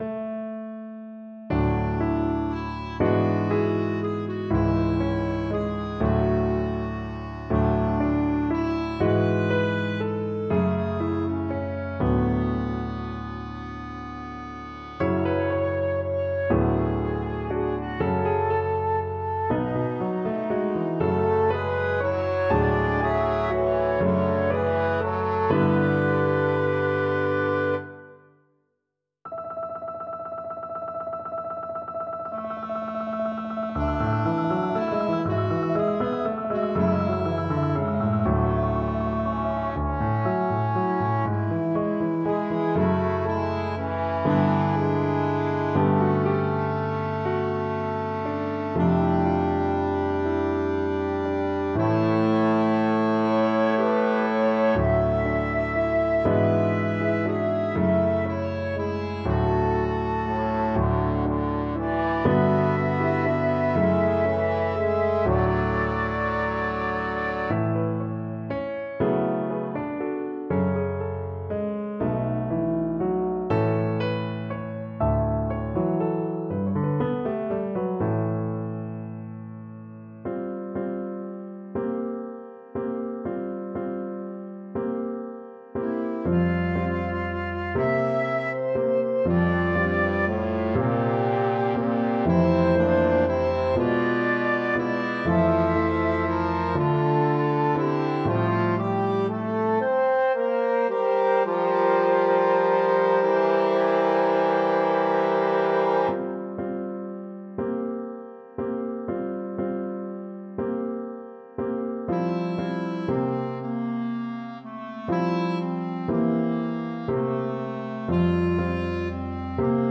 I updated the lyrics and wrote an SAB choral setting.